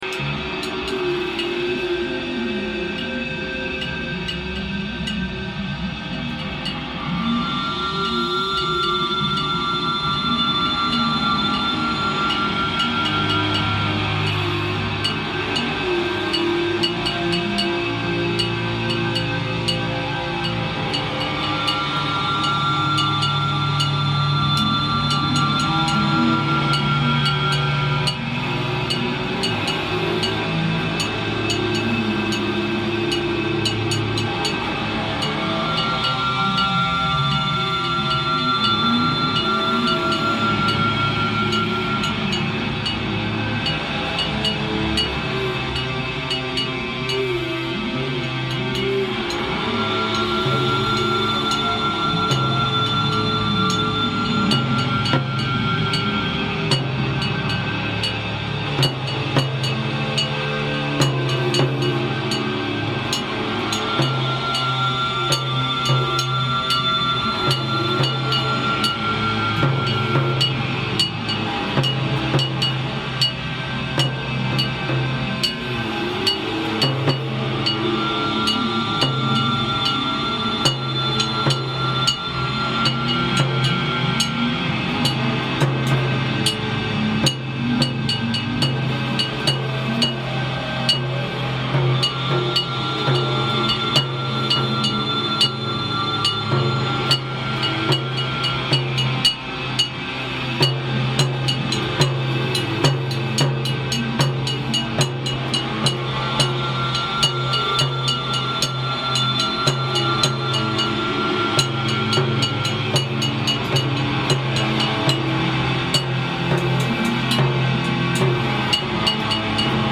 voice and percussion
noise, bass, and keyboards
guitars